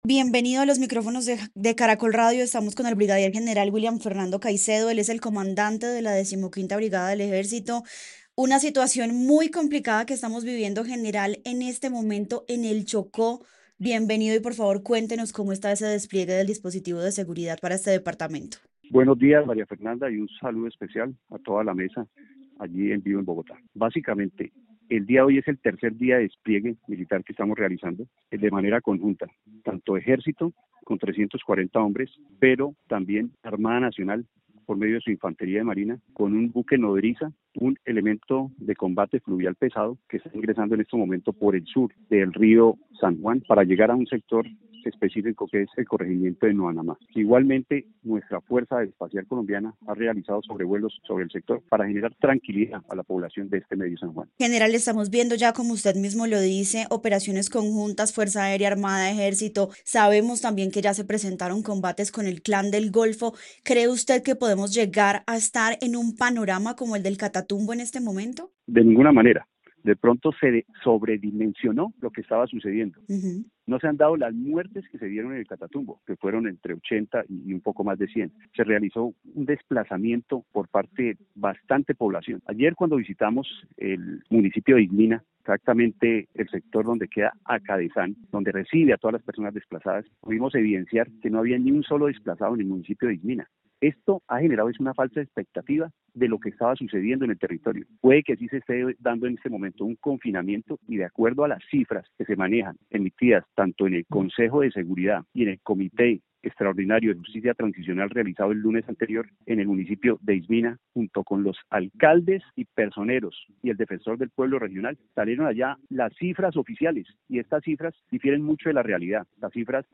En entrevista con Caracol Radio, el brigadier general William Fernando Caicedo, comandante de la Decimoquinta Brigada del Ejército, informó sobre el despliegue militar en el Medio San Juan, Chocó, y aclaró las cifras oficiales de desplazamiento y confinamiento en la región.